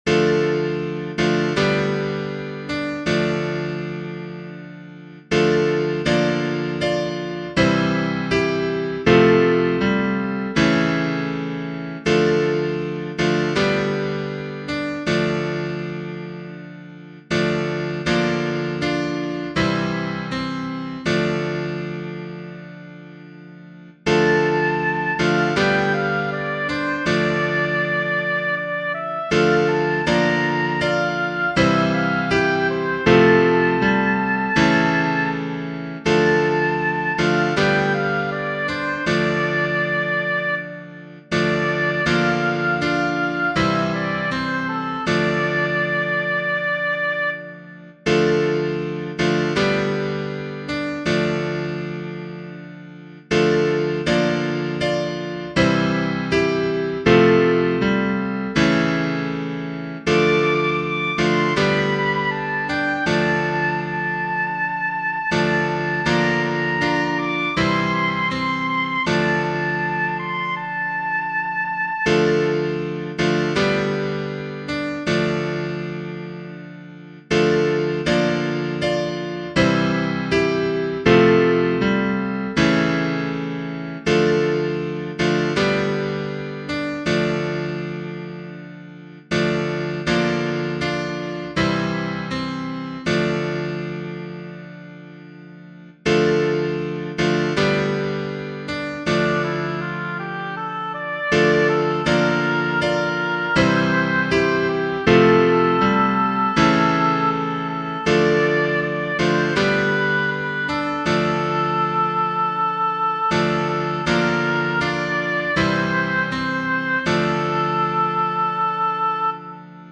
Berthier, J. Genere: Religiose Text: Psalm 103 Chant: Bless the Lord, my soul, and bless God's holy name.